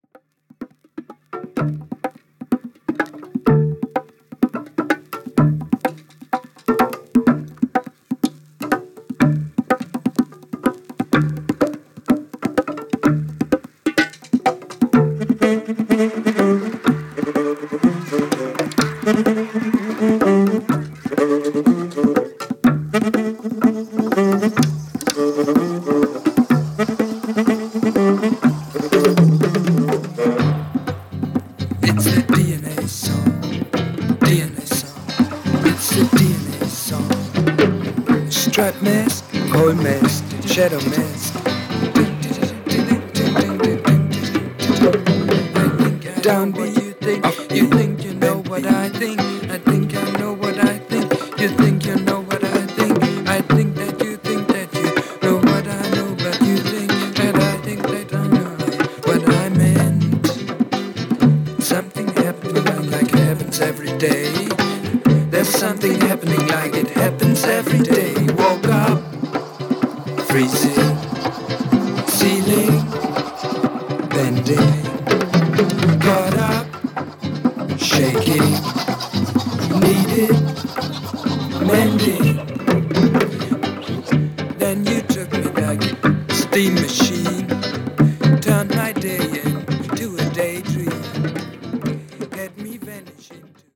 75年リリース・良い意味で力の抜け方がステキです！！！